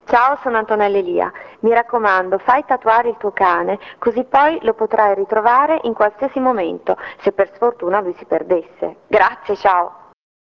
ASCOLTA GLI SPOT DI ANTONELLA ELIA